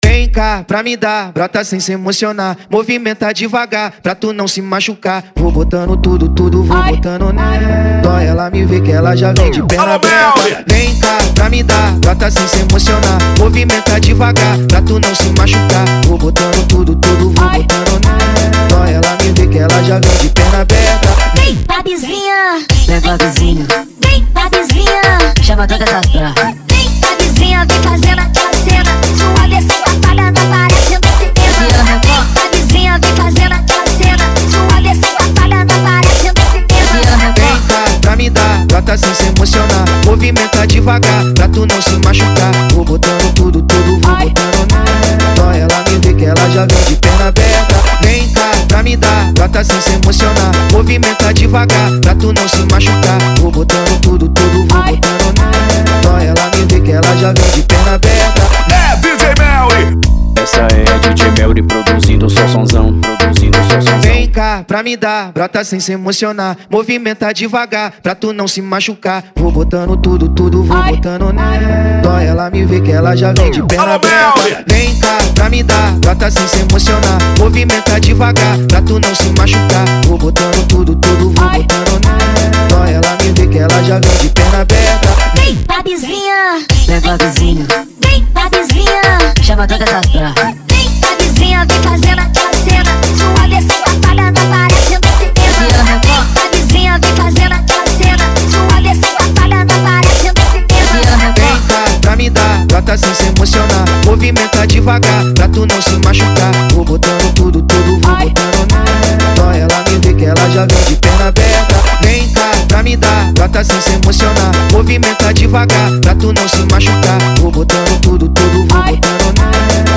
Tecnofunk